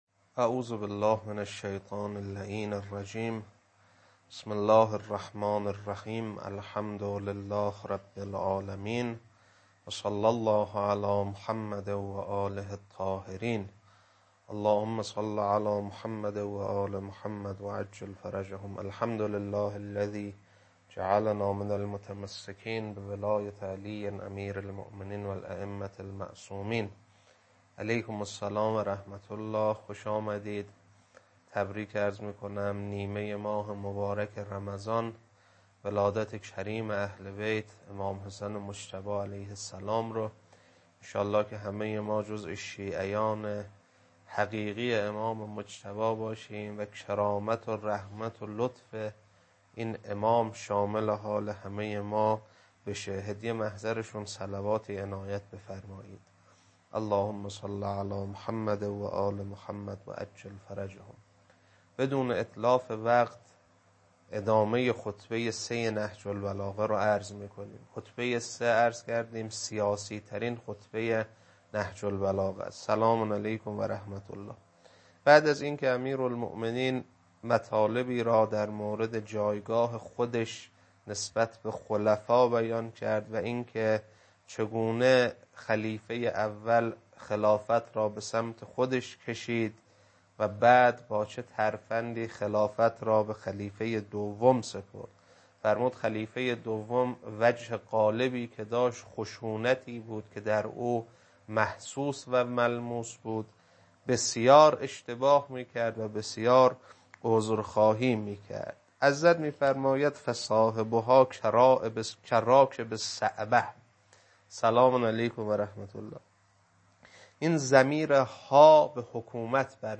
خطبه 3 بخش دوم.mp3
خطبه-3-بخش-دوم.mp3